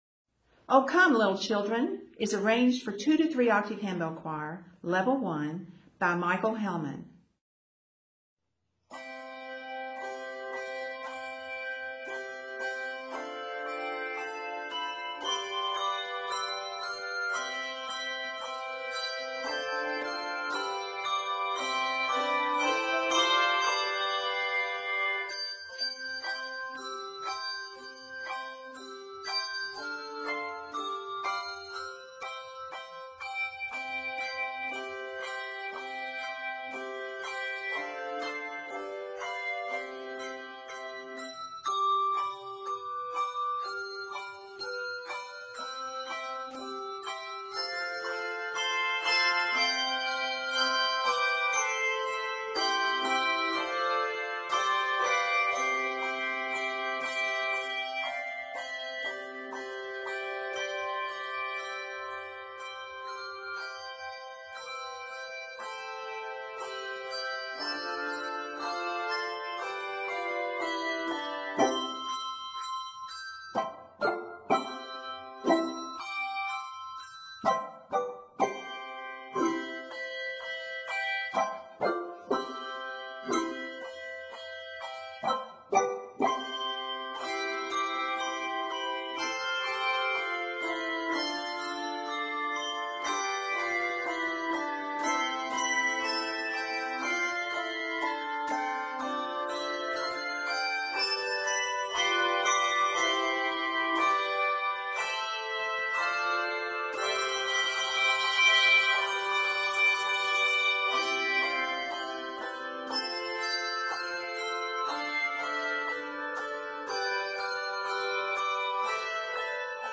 for 2-3 octave handbell choir
delightful, energetic setting
Christmas carol